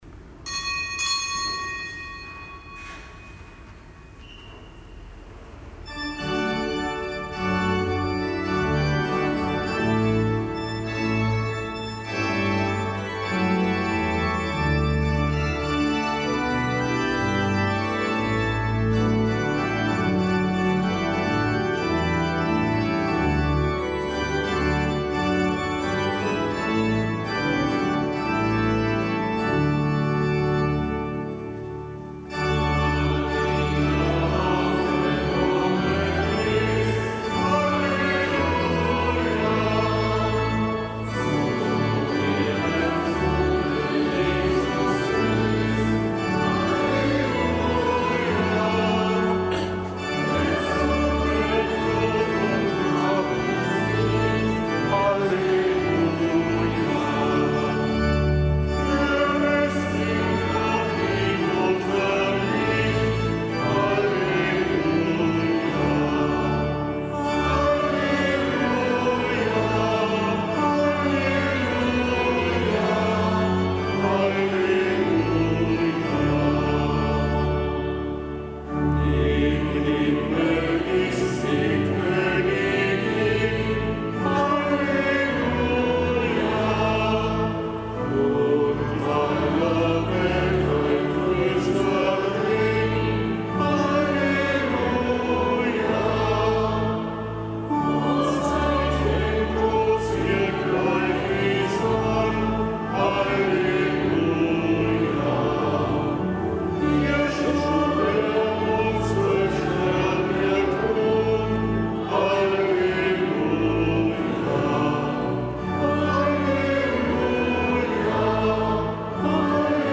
Kapitelsmesse aus dem Kölner Dom am Hochfest Mariä Aufnahme in den Himmel.